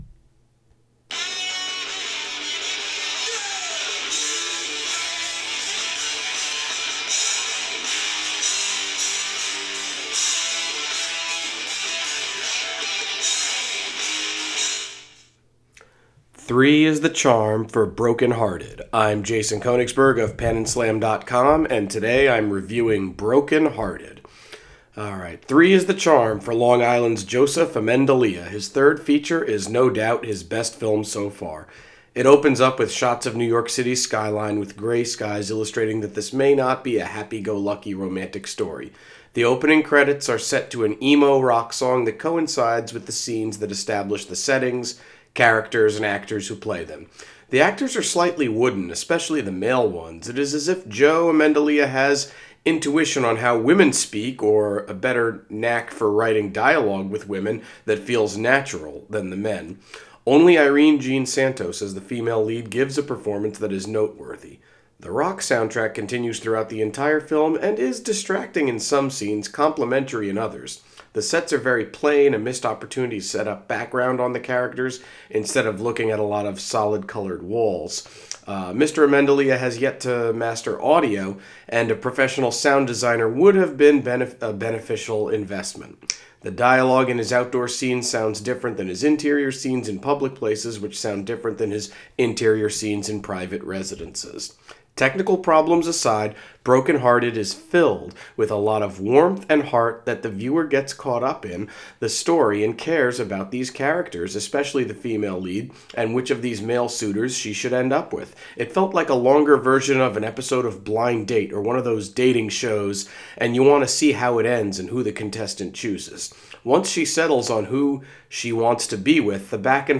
Movie Review: Broken Hearted